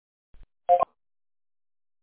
These are the most common ViciDial answering sounds
and BLOOP